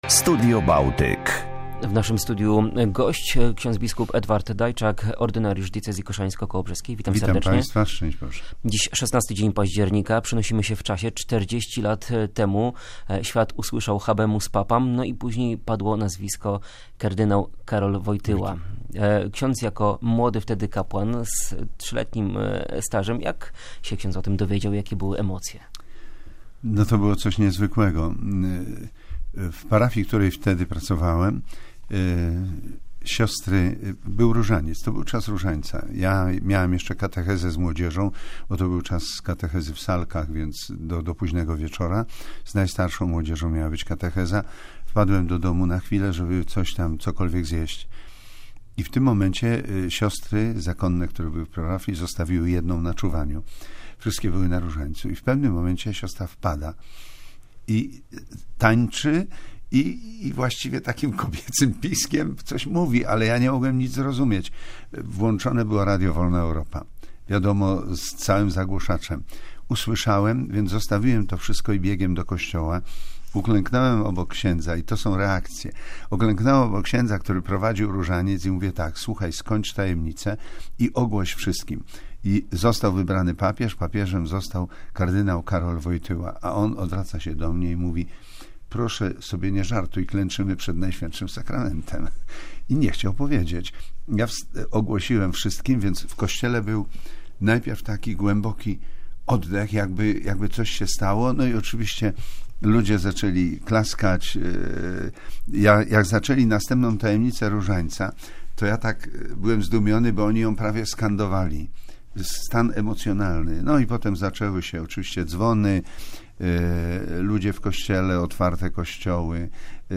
Rozmowa z bp. Edwardem Dajczakiem na antenie Polskiego Radia Koszalin.